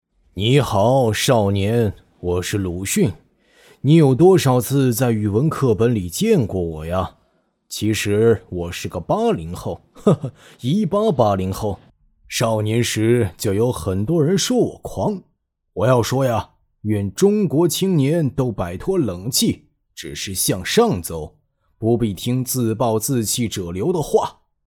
14 男国472_动画_模仿_模仿鲁迅 男国472
男国472_动画_模仿_模仿鲁迅.mp3